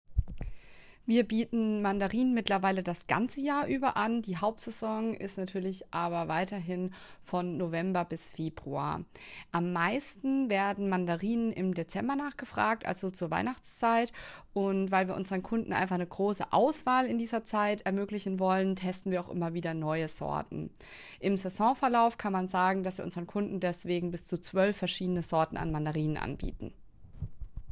O-Ton Mandarinen